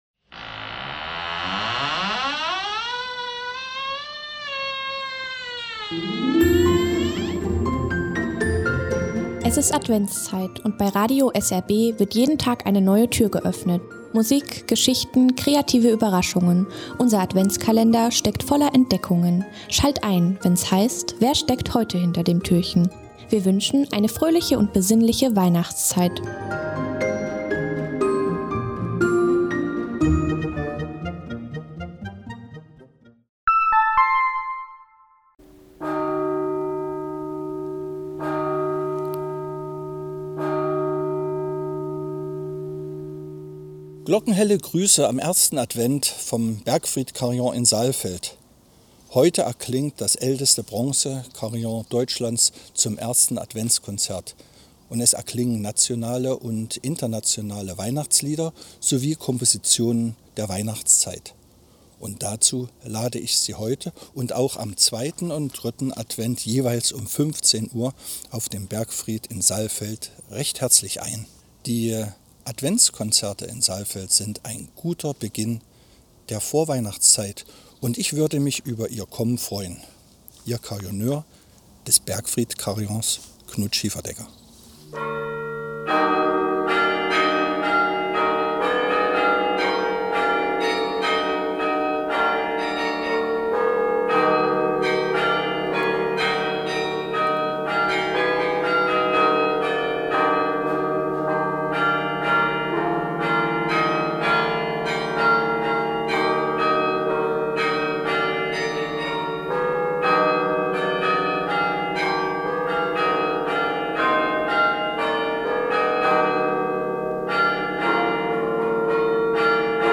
Carilloneur